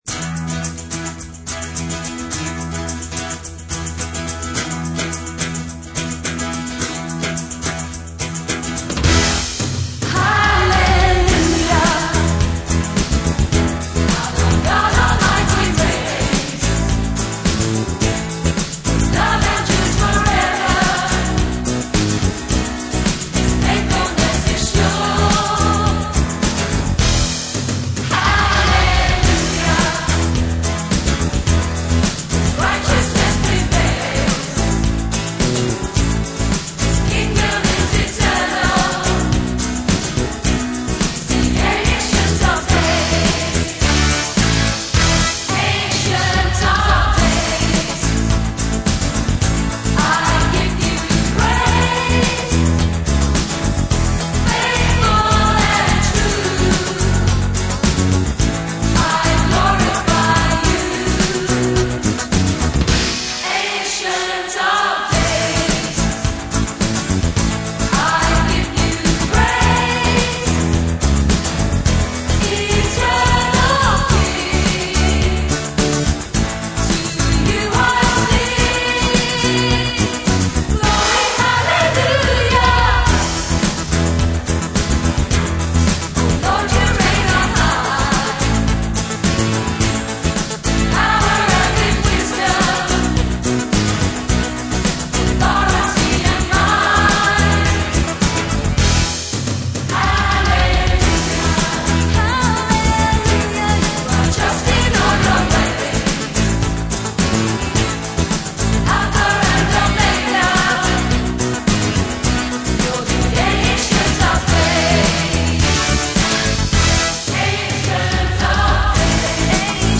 Very strumy guitar based song